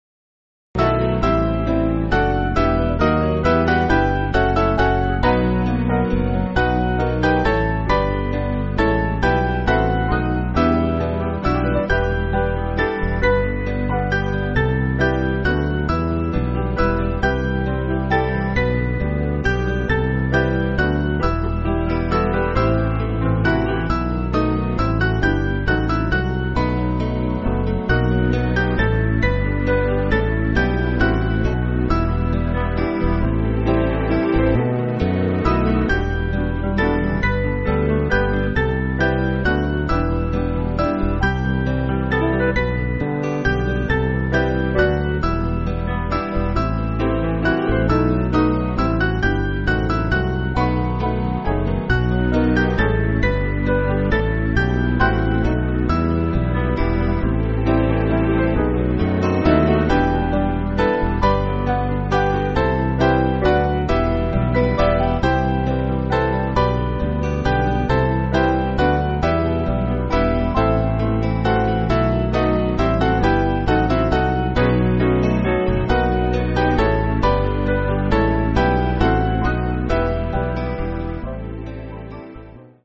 Mainly Piano
(CM)   8/Em-Fm
Alleluia Introduction